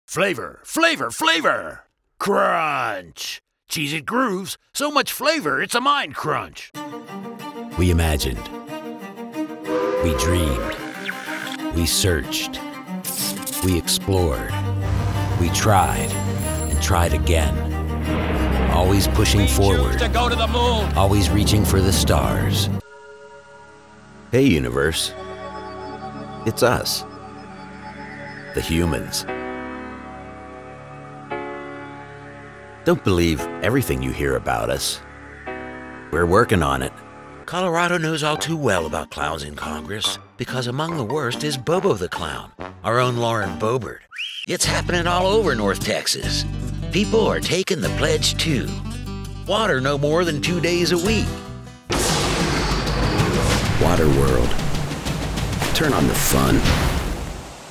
A versatile VO pro with a deep, warm voice and distinctive overtones.
Sprechprobe: Sonstiges (Muttersprache):
My voice is like smooth gravel; deep, textured, and unforgettable. Laid-back to high-energy.
I work from a broadcast-quality home studio equipped with Source-Connect.